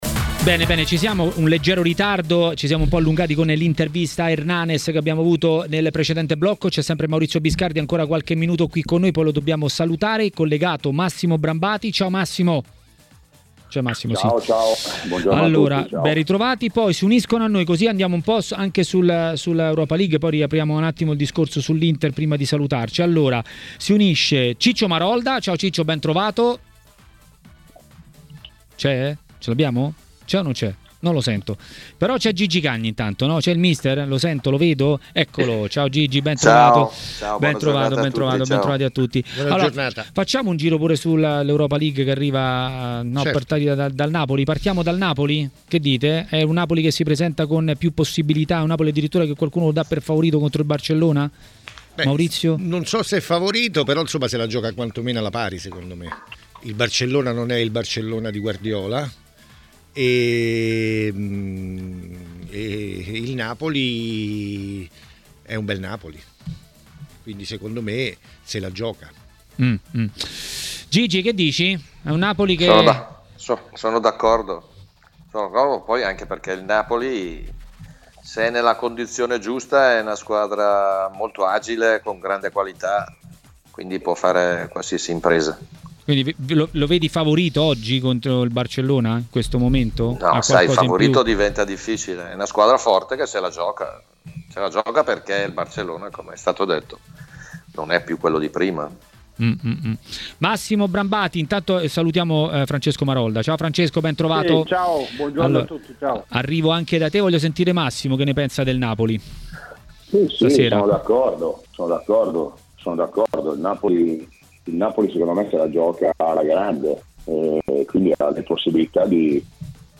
Mister Gigi Cagni a Maracanà, trasmissione di TMW Radio, ha parlato delle italiane nelle coppe.